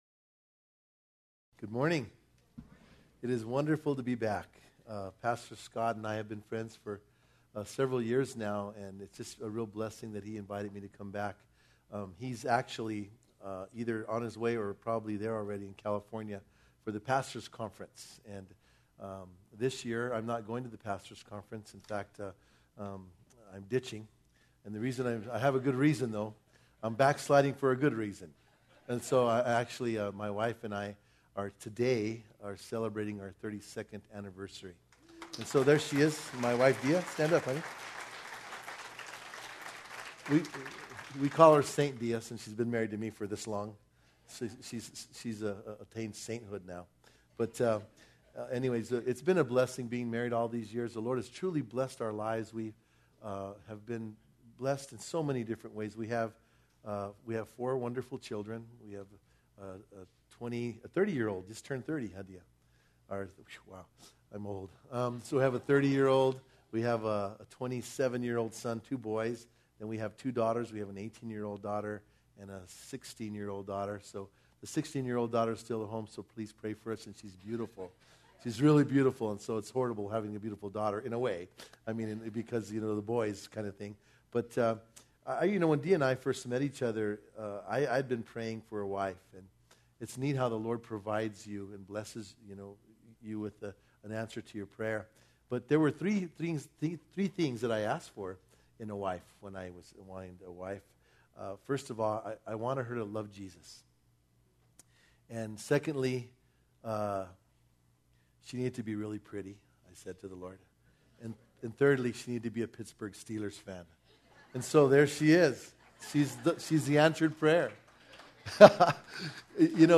Guest Speaker Passage: Matthew 18:21-35 Service Type: Sunday Morning « Acts 15 Missionary Report